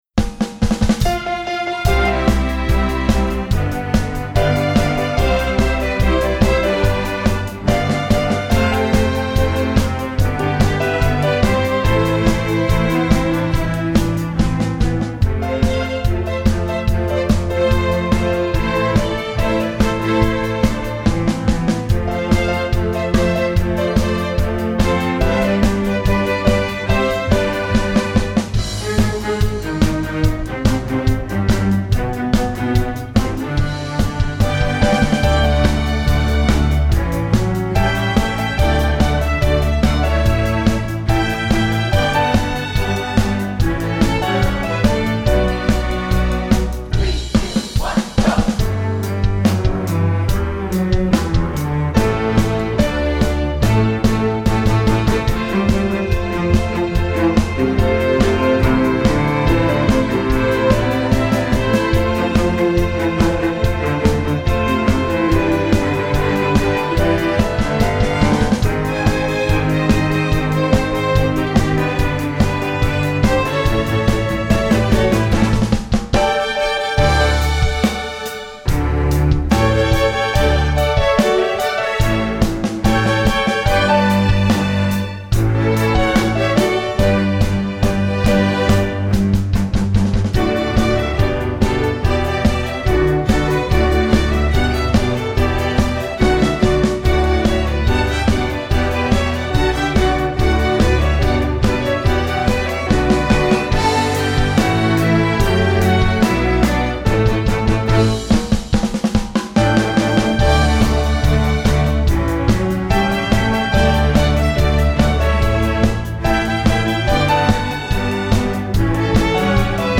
film/tv, movies